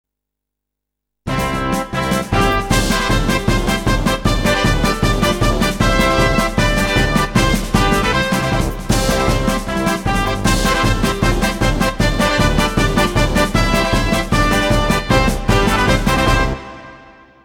без слов
инструментальные
марш